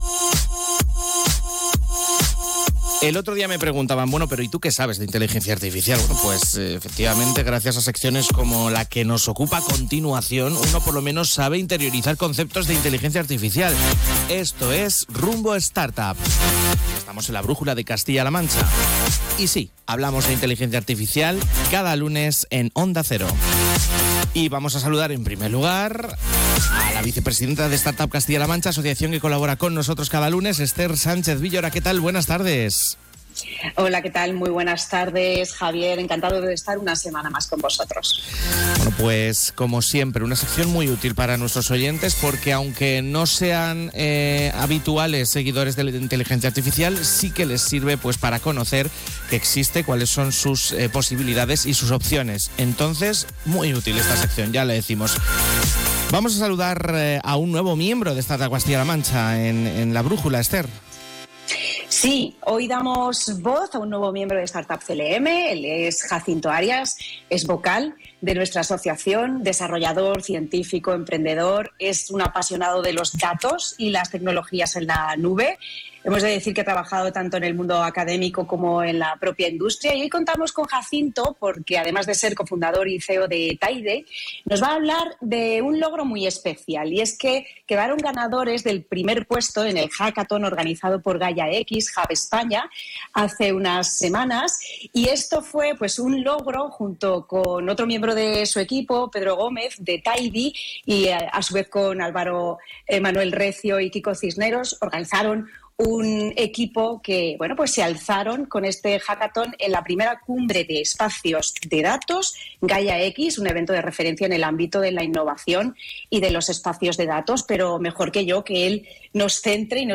Escucha la apasionante charla completa aquí en Onda Cero para conocer todos los detalles de este evento emocionante y cómo tú también puedes ser parte de la innovación en movimiento.